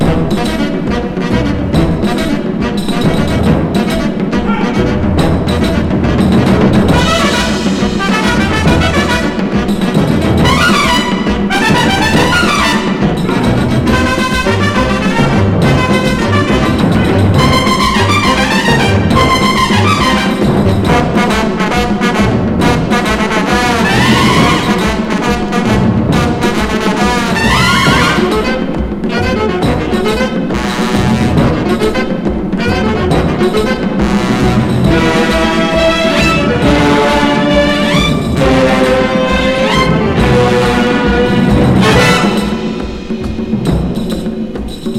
金管楽器の様々な表情、ストリングスにフルートが醸し出す魅惑さ、打楽器が生み出す土着さと洗練さを織り成す強烈かつ大興奮。
Jazz, Latin, Easy Listening, Mambo　USA　12inchレコード　33rpm　Mono